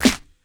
07_Clap_01_SP.wav